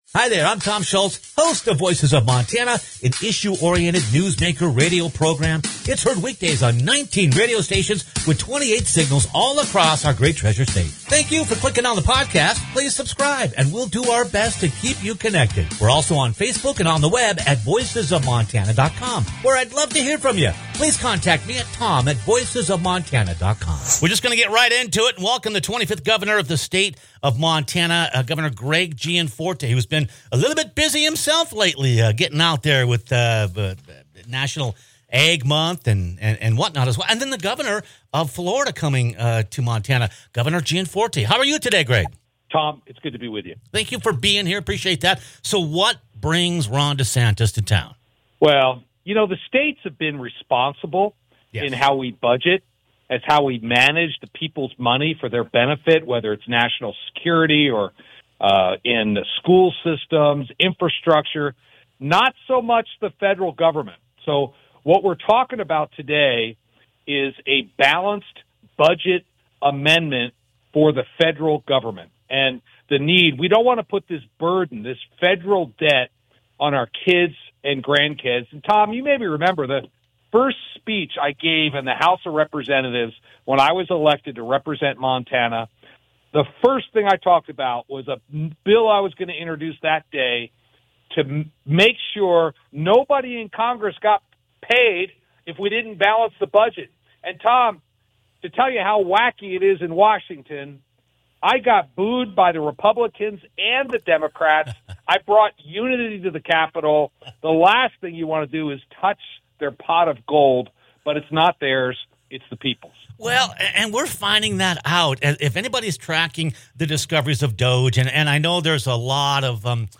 With the national debt at $36 trillion and growing 5 billion daily, two of America's most fiscally conservative governor's, Montana Governor Greg Gianforte, and Florida Governor Ron DeSantis, join forces to draw attention to this country's potentially economically crippling debt. Click on the podcast as Governor Gianforte discusses how to restrain government spending, as well